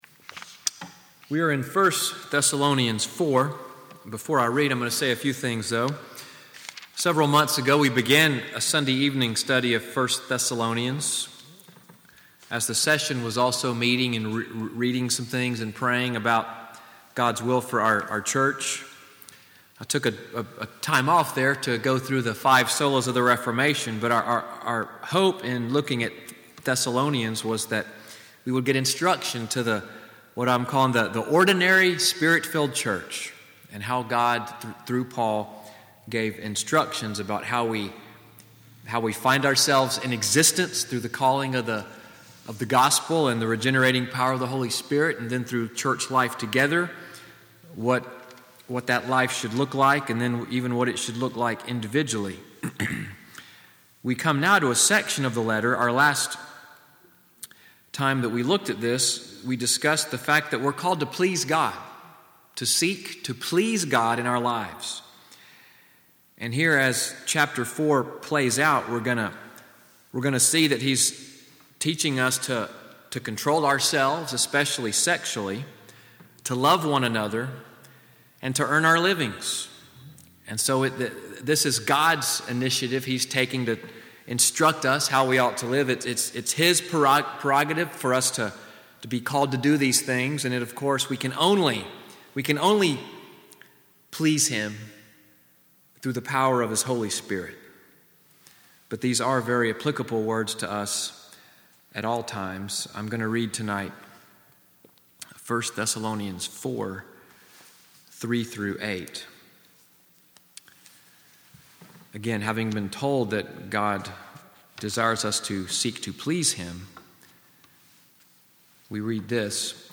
EVENING WORSHIP at NCPC-Selma, audio of the sermon, “God’s Will for You,” November 12, 2017.